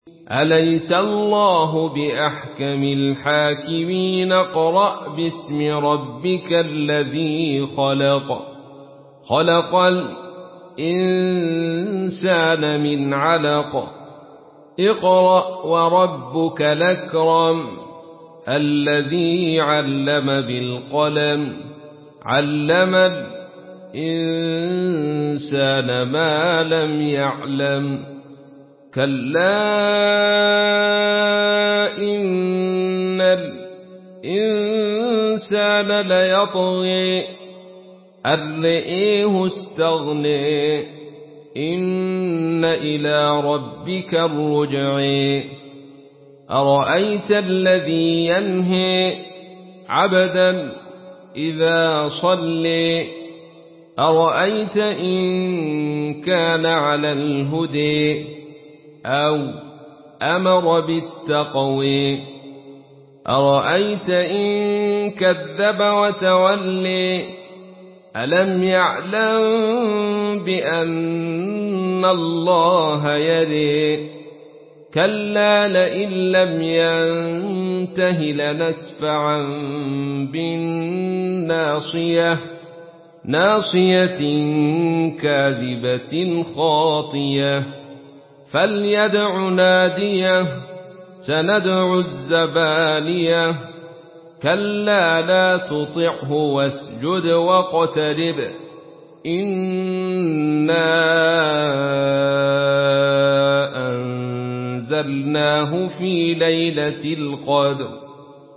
Surah Alak MP3 in the Voice of Abdul Rashid Sufi in Khalaf Narration
Murattal